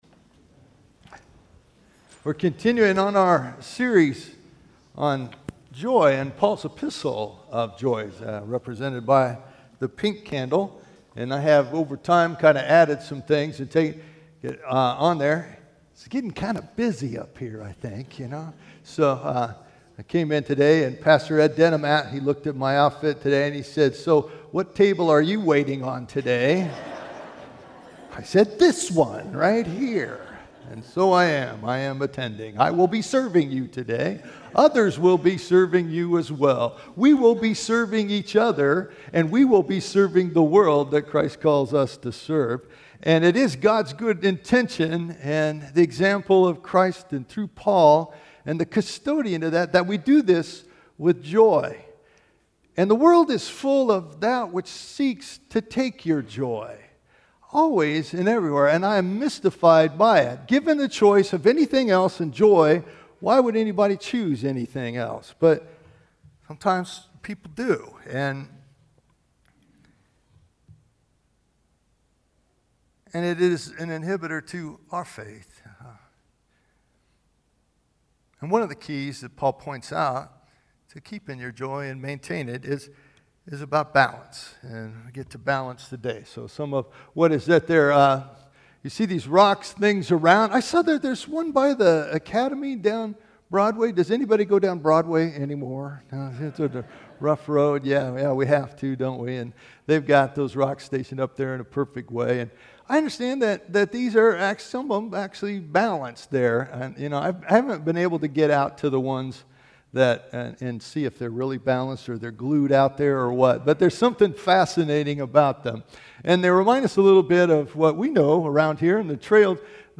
Today we continue our sermon series on “Joy.” I thought it would be a good idea to begin this year with teaching about Joy – what it is, what it is not, how do we keep it, how do we keep others from stealing it and how do we hold onto it when our hearts are breaking. We’ll be looking for inspiration and guidance from Paul’s letter to the Church at Philippi.